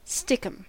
Ääntäminen
Ääntäminen US UK : IPA : /ˈstɪkəm/ Haettu sana löytyi näillä lähdekielillä: englanti Käännöksiä ei löytynyt valitulle kohdekielelle.